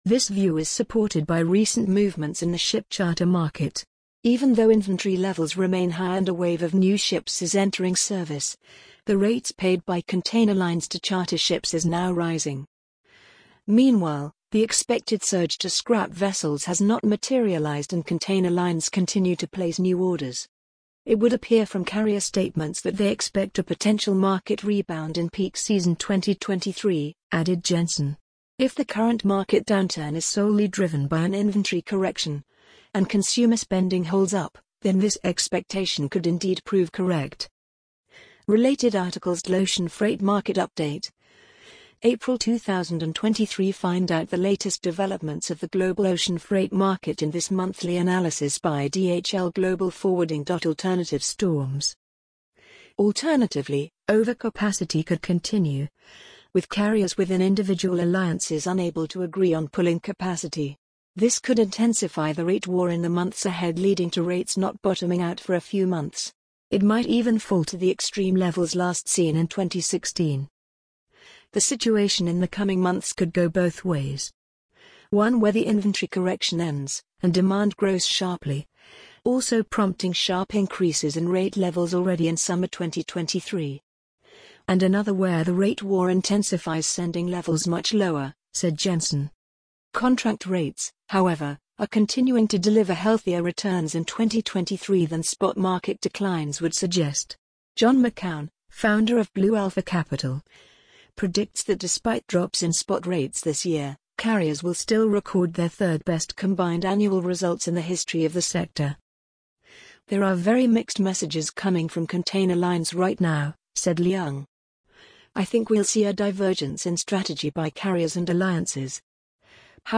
amazon_polly_35320.mp3